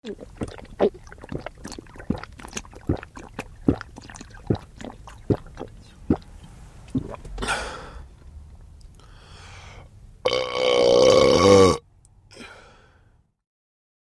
Отрыжка у человека - Вариант 2